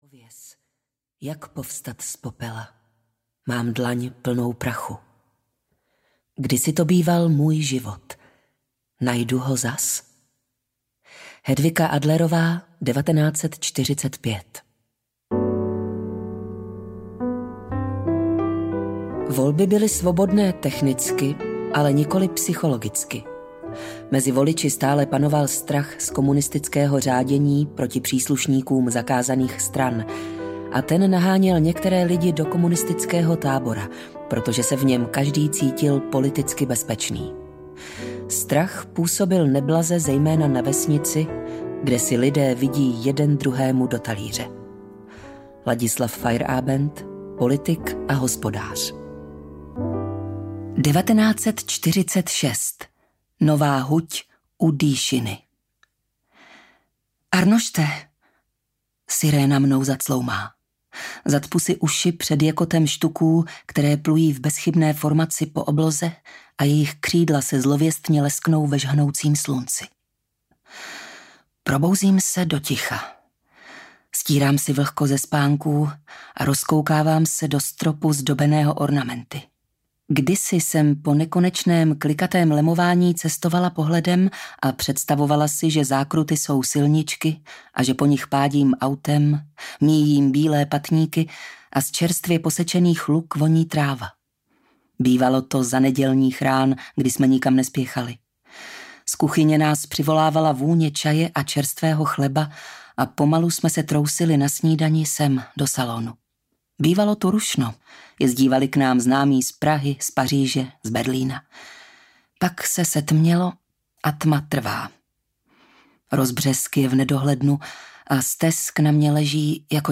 Nultá hodina audiokniha
Ukázka z knihy